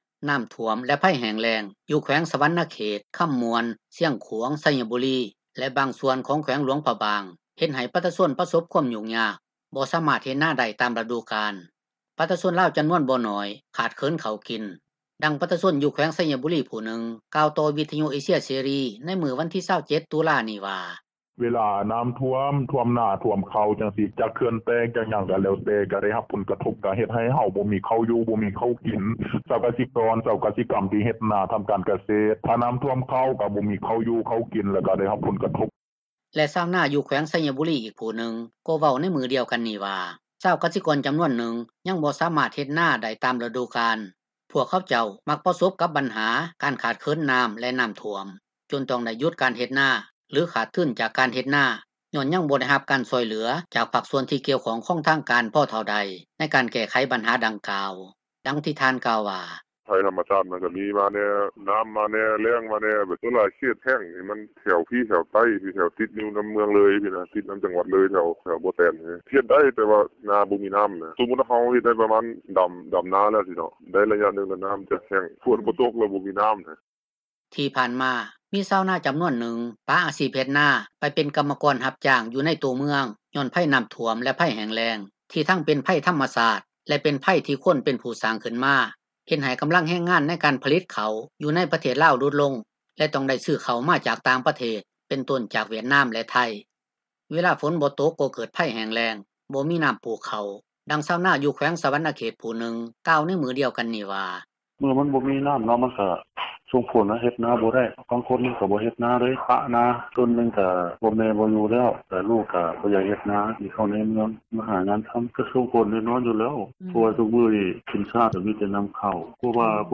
ນັກຂ່າວ ພົລເມືອງ